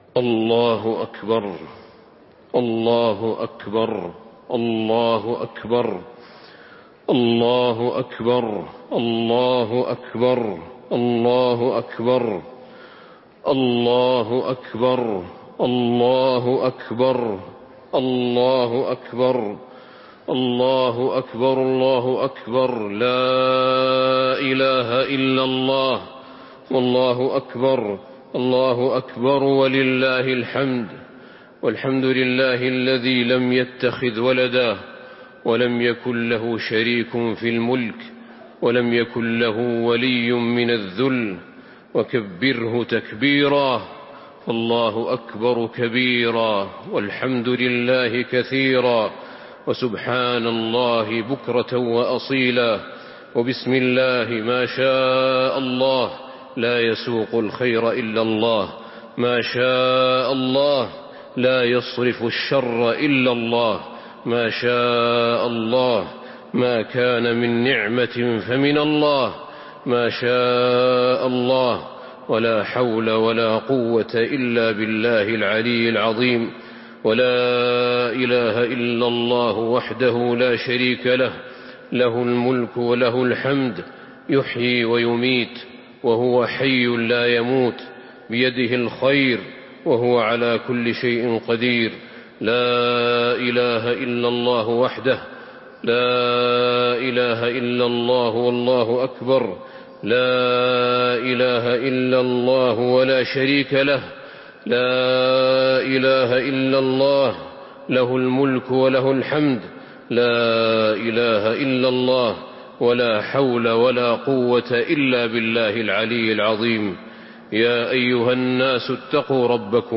خطبة الاستسقاء - المدينة - الشيخ أحمد بن طالب - الموقع الرسمي لرئاسة الشؤون الدينية بالمسجد النبوي والمسجد الحرام
تاريخ النشر ٢٠ رجب ١٤٤٥ هـ المكان: المسجد النبوي الشيخ: فضيلة الشيخ أحمد بن طالب بن حميد فضيلة الشيخ أحمد بن طالب بن حميد خطبة الاستسقاء - المدينة - الشيخ أحمد بن طالب